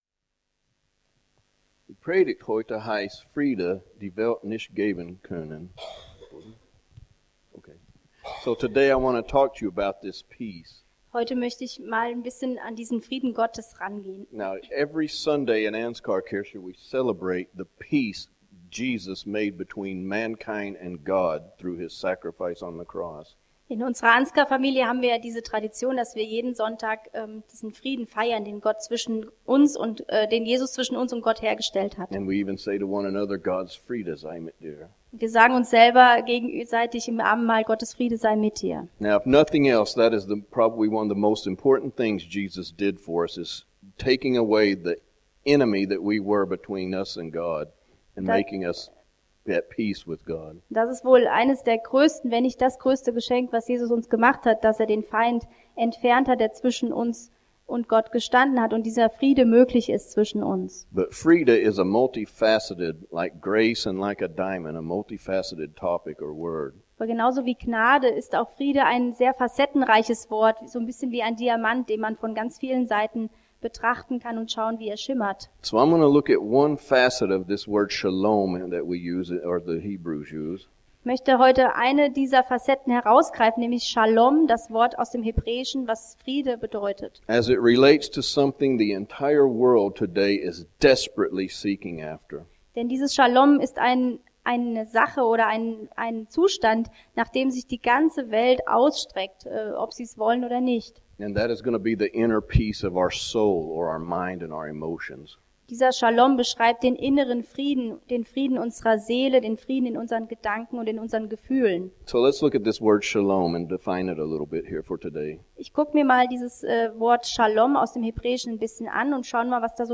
In seiner Predigt vom 29.